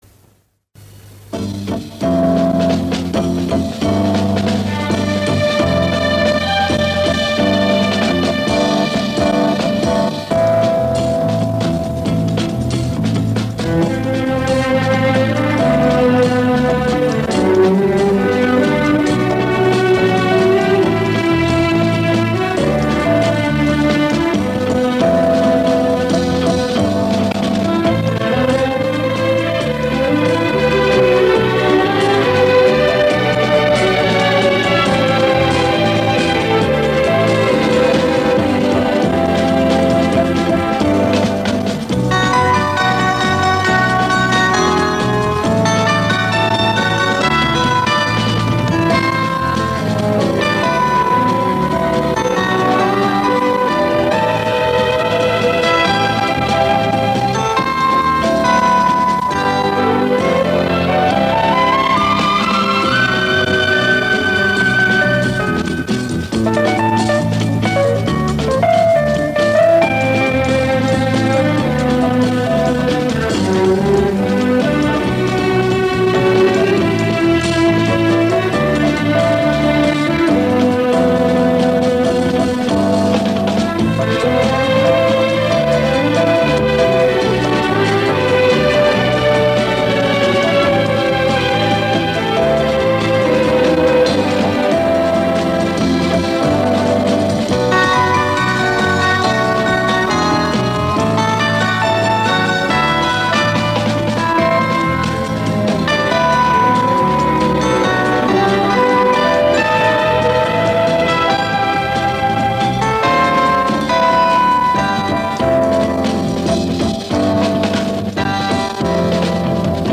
Очень красивая мелодия!